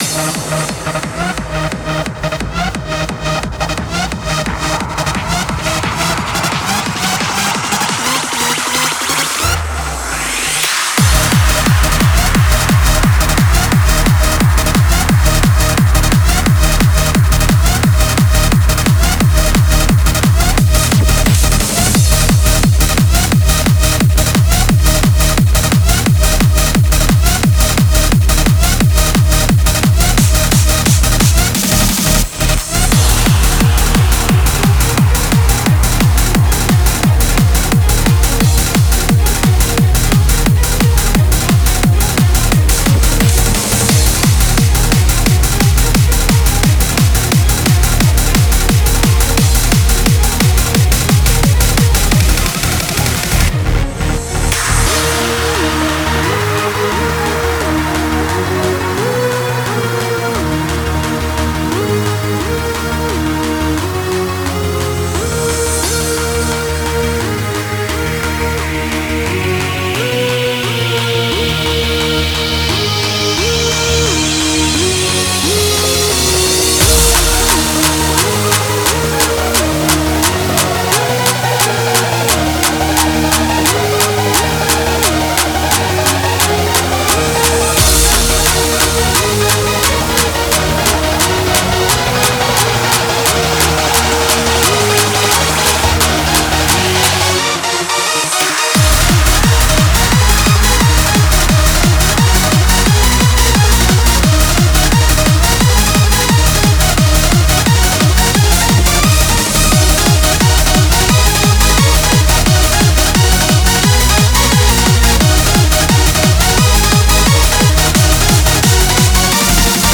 BPM175
Audio QualityMusic Cut
GENRE: LUNAR DANCE SPEED